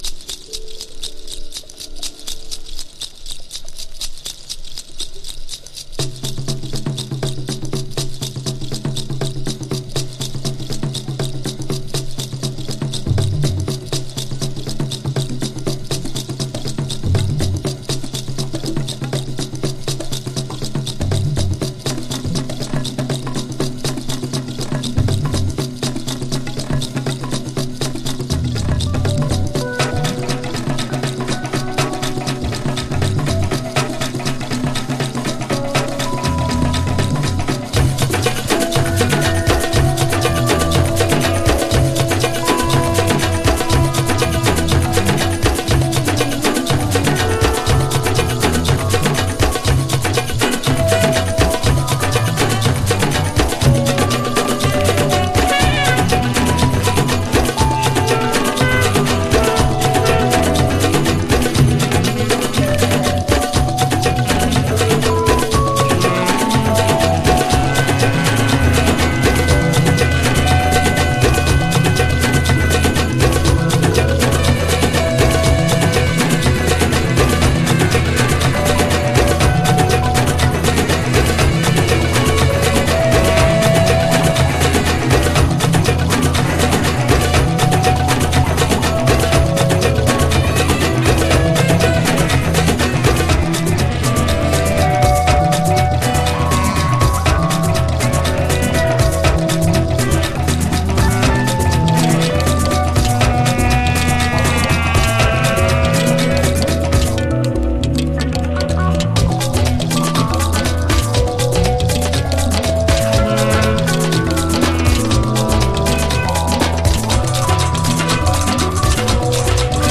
ジャポン産らしい丁寧な中域のサイケデリック成分。